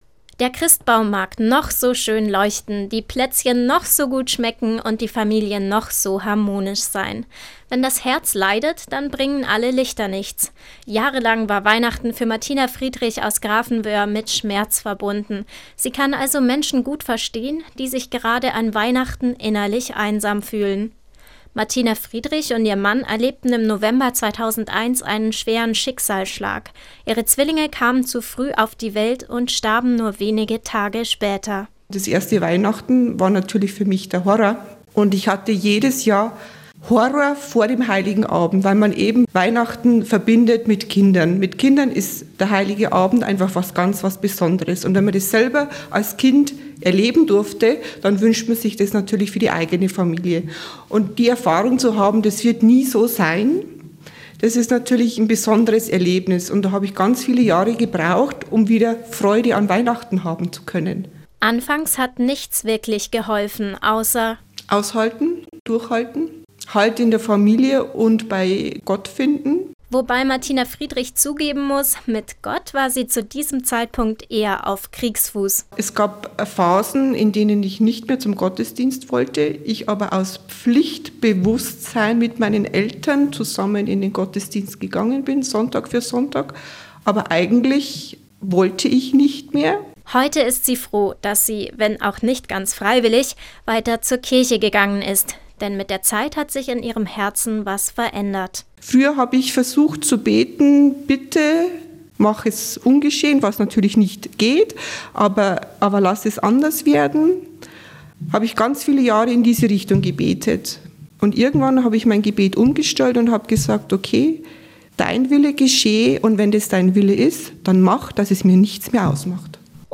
Radio-Interview